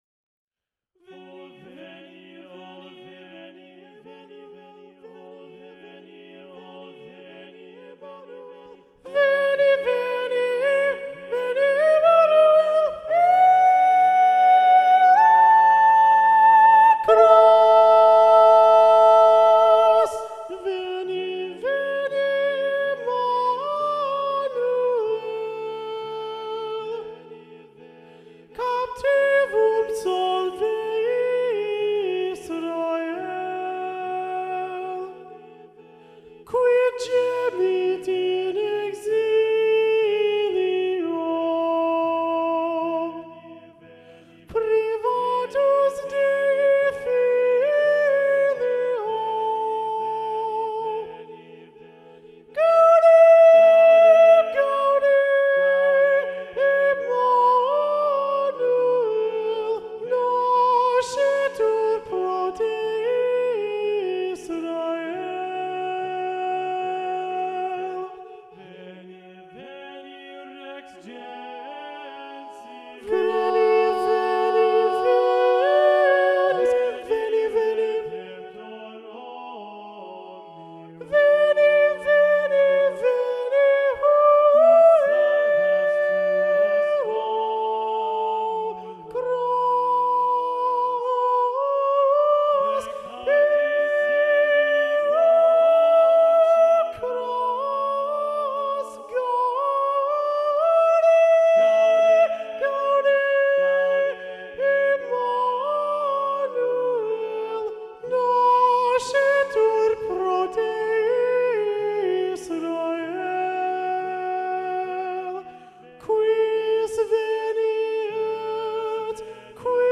Veni, Veni Emmanuel SATB – Soprano 1 Predominant – Micahel John Trotta